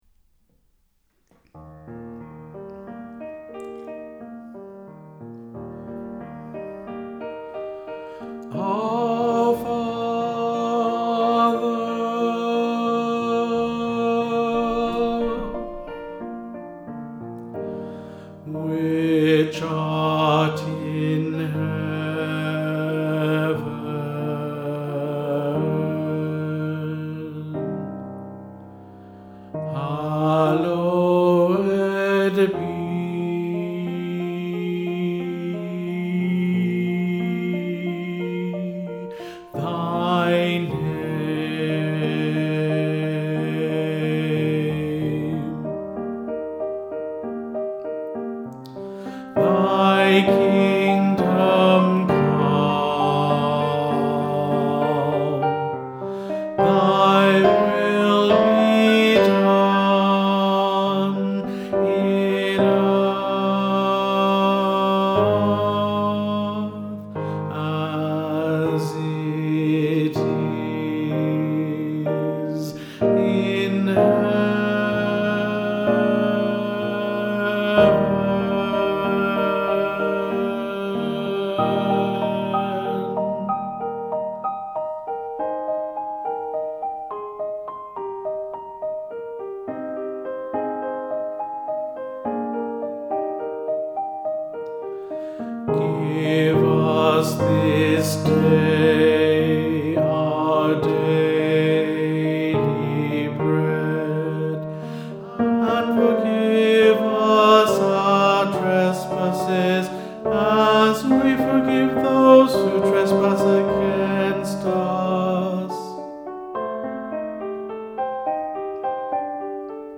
Lords Prayer Baritone - Rame Peninsula Male Voice Choir
Lords Prayer Baritone